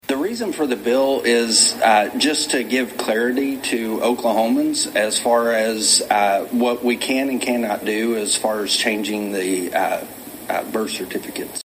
CLICK HERE to listen to commentary from Republican House member Kevin West.